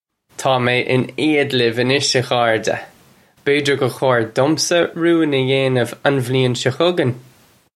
Pronunciation for how to say
Taw may in ade liv anish, uh khar-juh. Bay-jir gurr khore dumsa roo-in uh yayniv un vlee-in shaw hug-in.